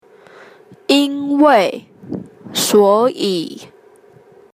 (請先仔細聆聽老師唸，再來，請你試著唸唸看。)